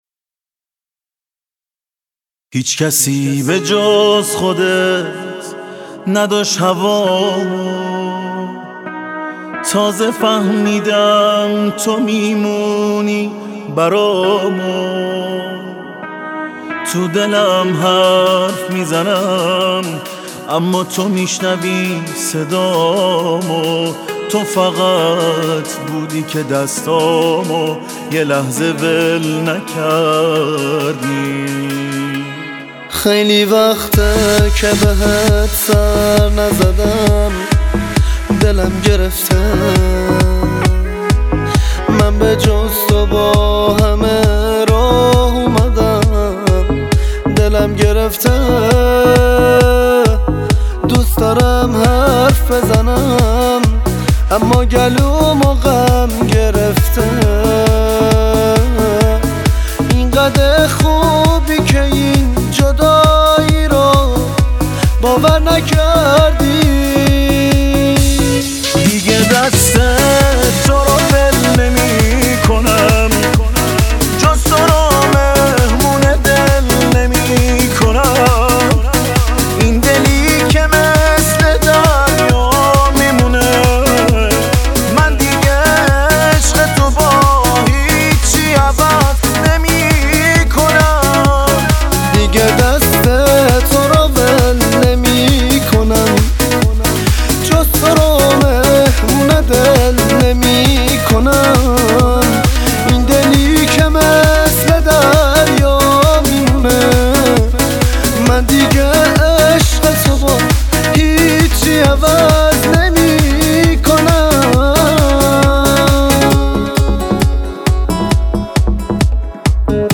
موضوع : آهنگ غمگین , آهنگ فارســی ,